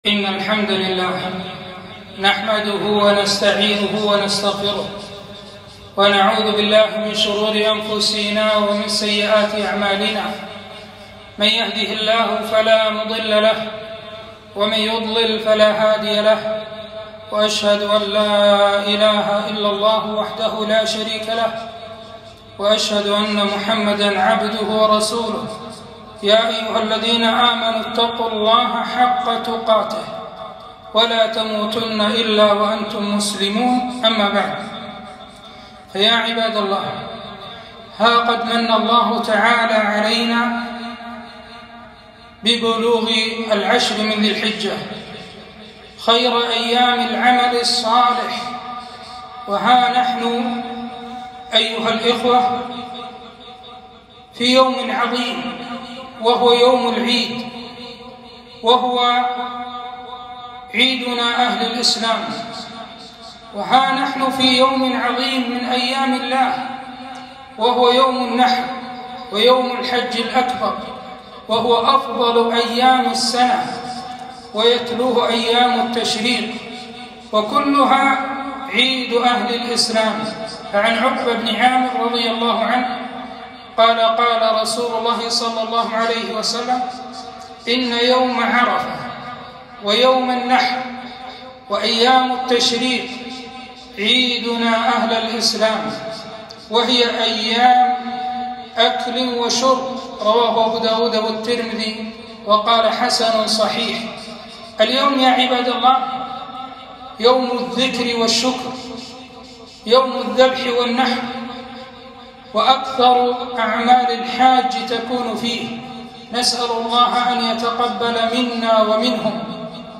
فضل اجتماع الكلمة - خطبة عيدالأضحى